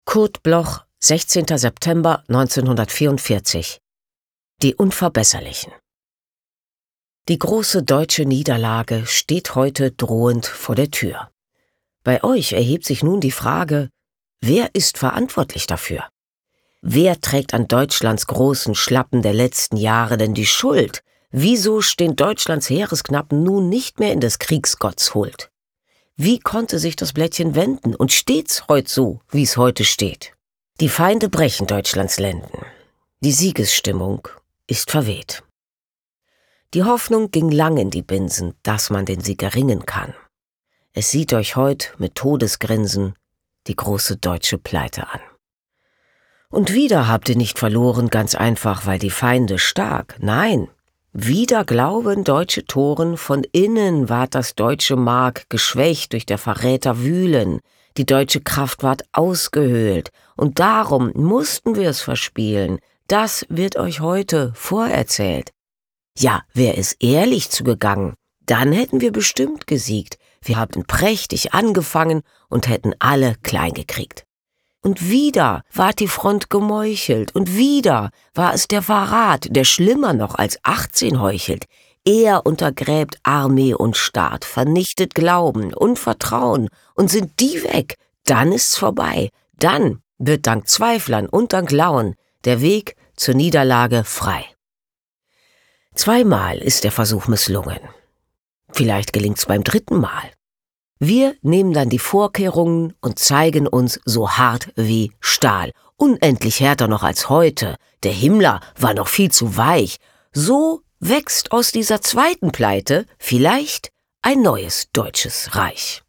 Aufnahme: Speak low, Berlin · Bearbeitung: Kristen & Schmidt, Wiesbaden